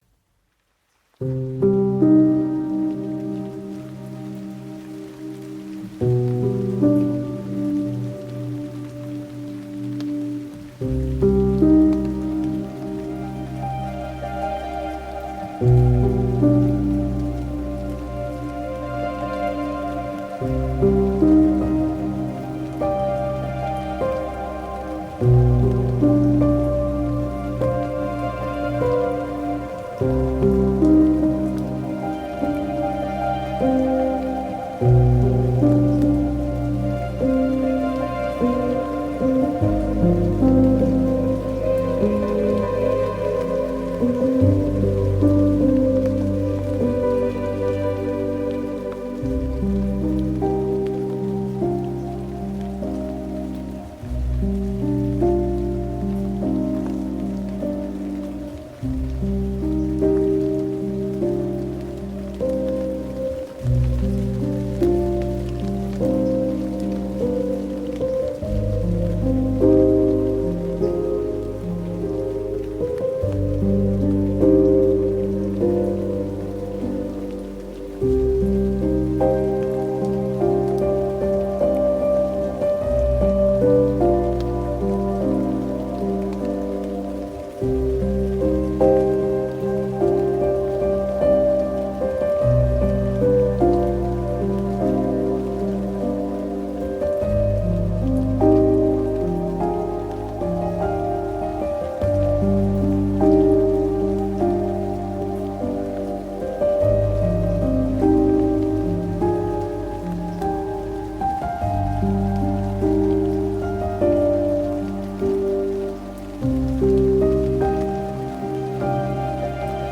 سعی کردم از سبک و زبانای مختلفی بزارم.
نوستالژیک و ملانکولی
فضای لطیف و احساسی
سبک فادو